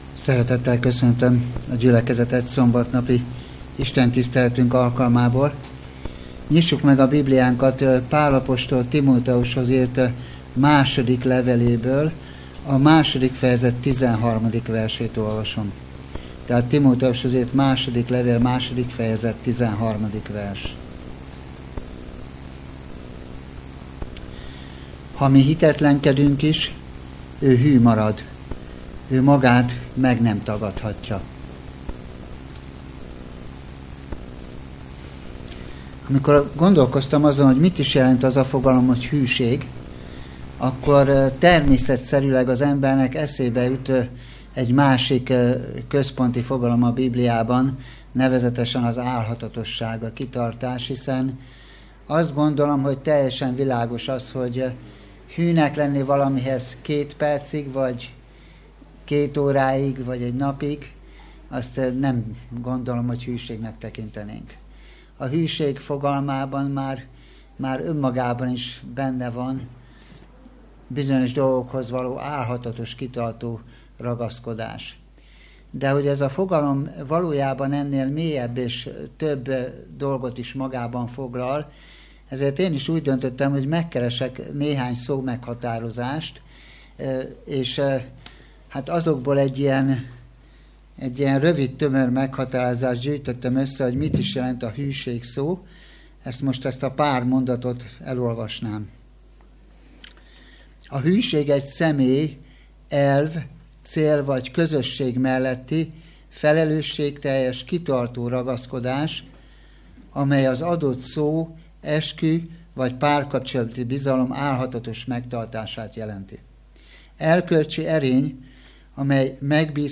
Hálaadó istentisztelet
Szombati igehirdetés